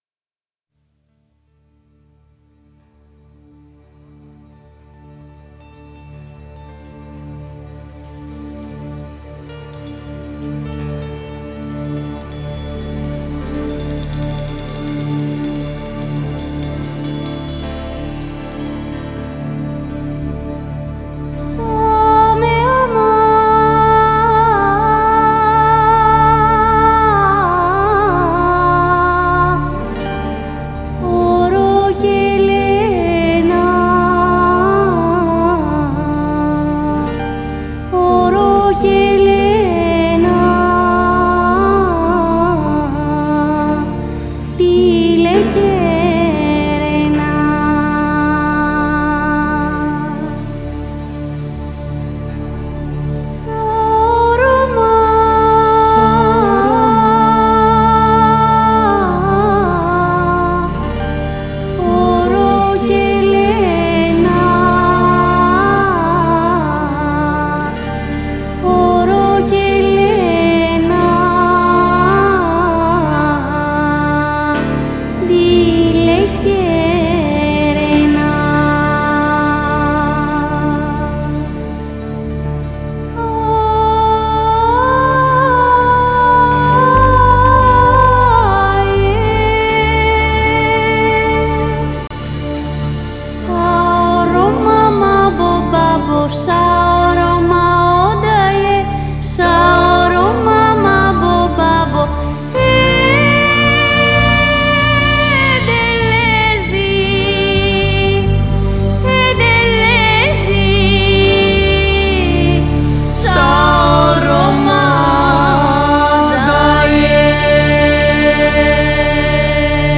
Genre: Balkan Folk, World Music